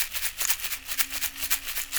African shaker-2 120bpm .wav